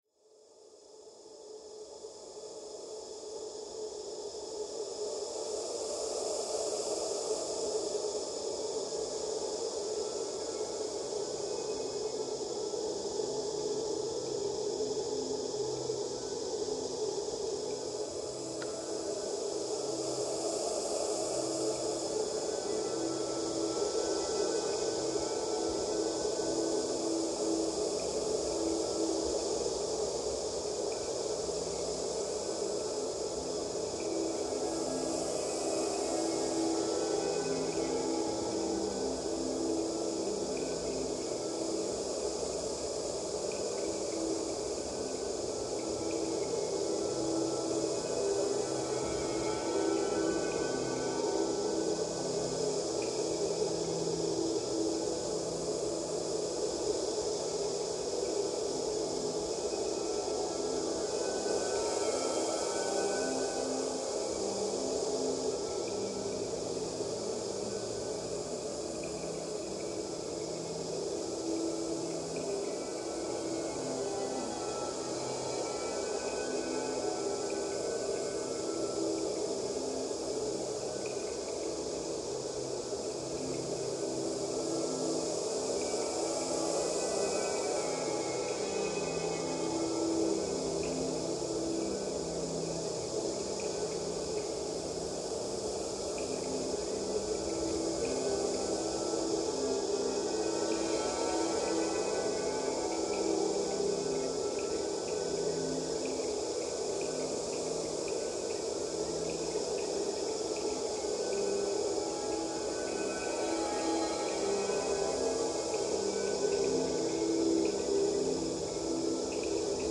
Skilpadkloof, South Africa reimagined